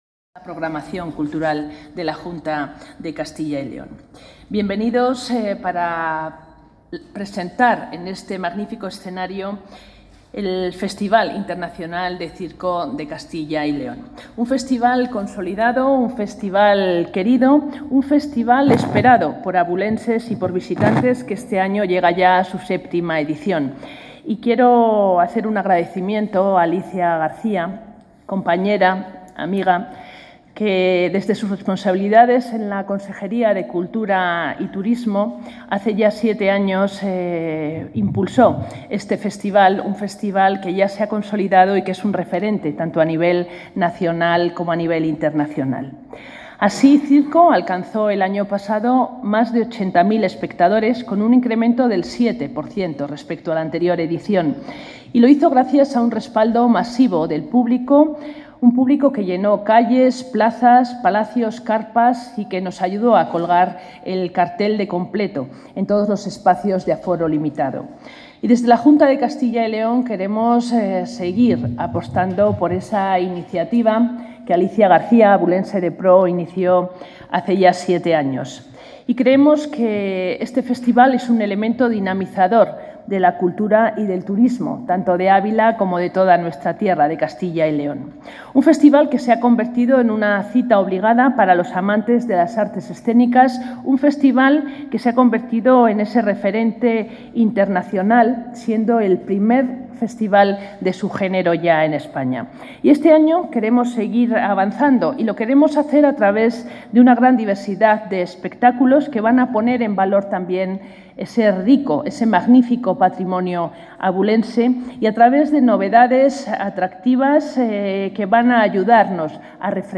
Intervención de la consejera de Cultura y Turismo.